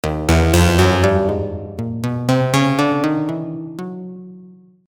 The Velocirapture tool is used to generate a series of notes with varying velocity levels.
the initial line